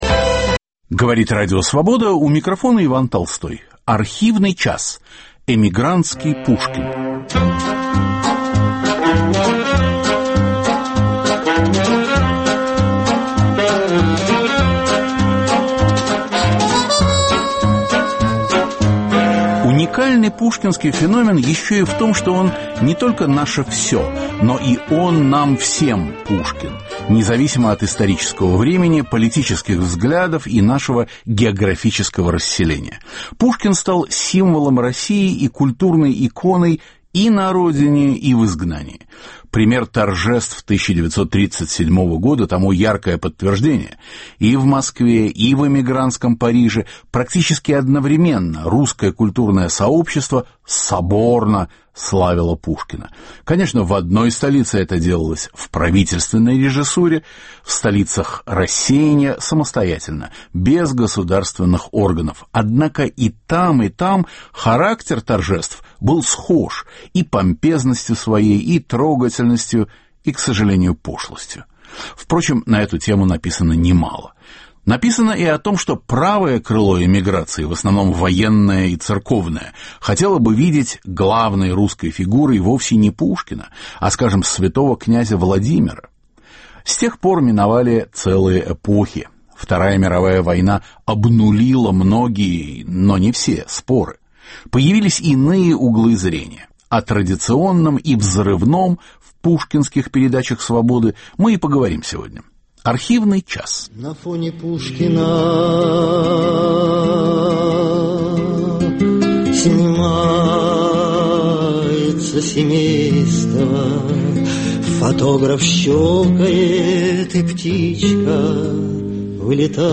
Протоиерей Александр Шмеман, искусствовед Владимир Вейдле, «скандалист» Абрам Терц – о «своем» Пушкине. Из архивов Радио Свобода.